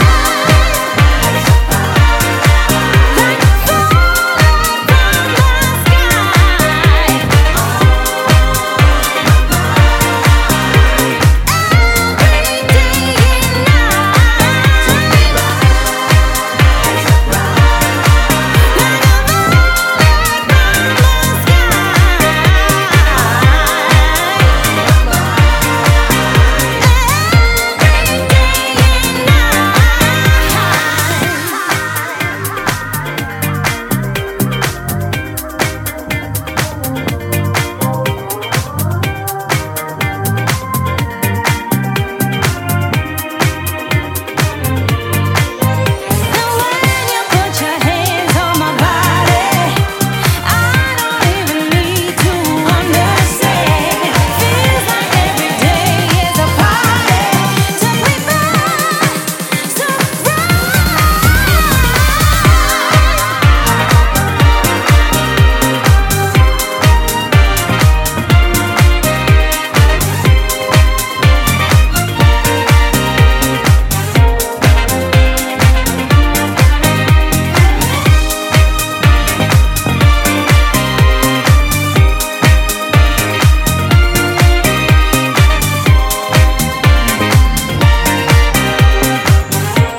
(B面)は各曲ともにソウルフル＆ディスコ〜ディープ・ハウスで捨曲一切無しです。
ジャンル(スタイル) DISCO / HOUSE